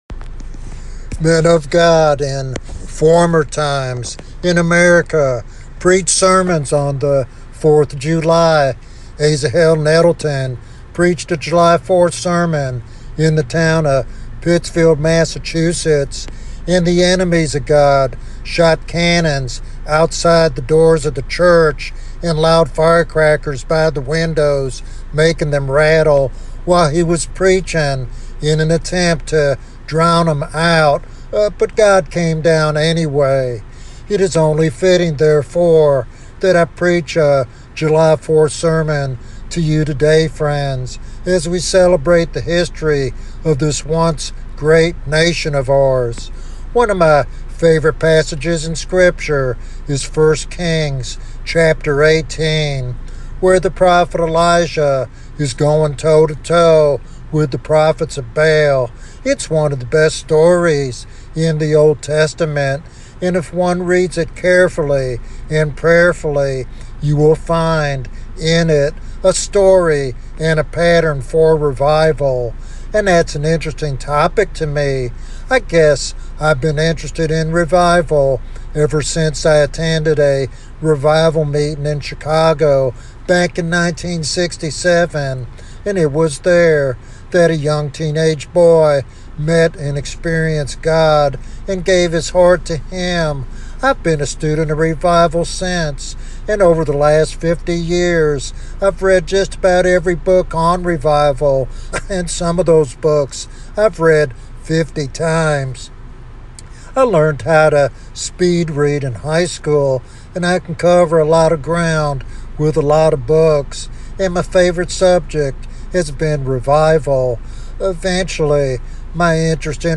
This prophetic message is a heartfelt call to pastors and believers alike to embrace the urgency of spiritual awakening in a nation desperately in need of God's intervention.